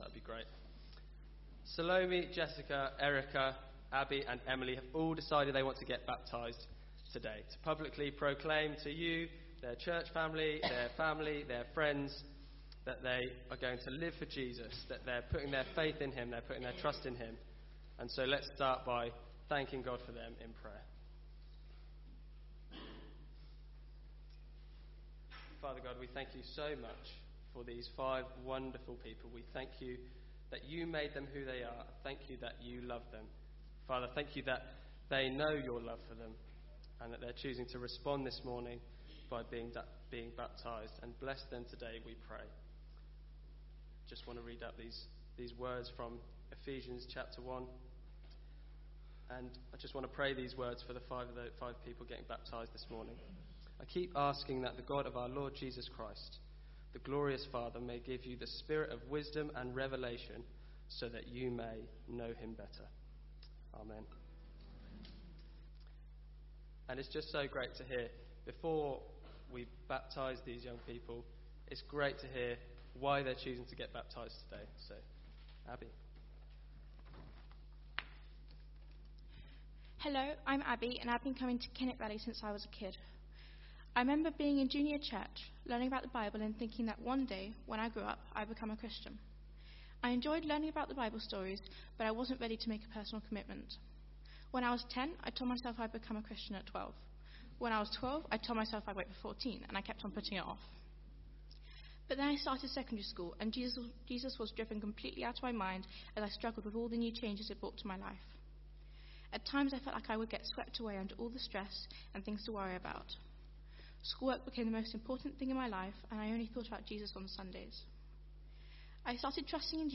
Baptismal Service – April 2019